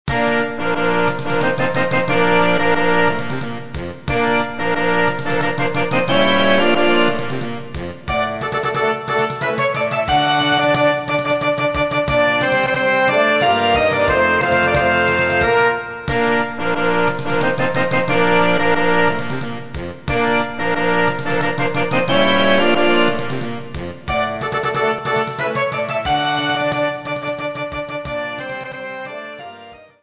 Klasyczna